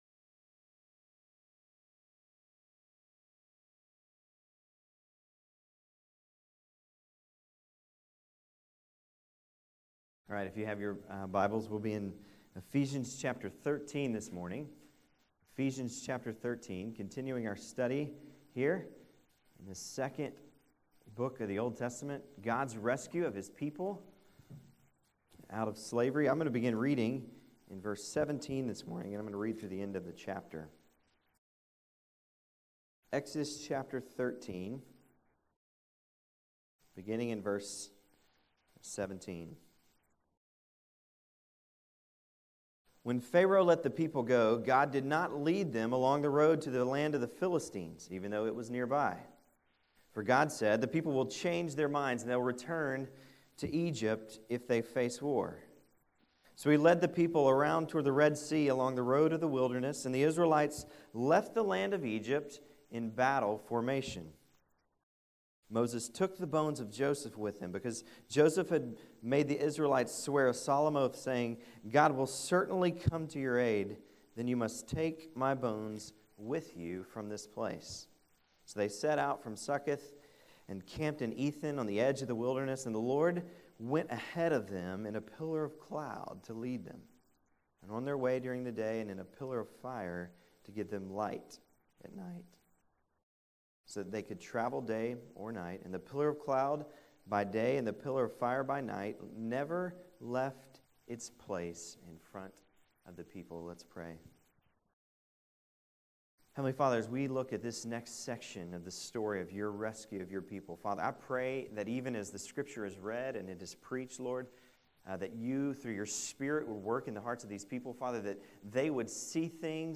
Exodus sermon series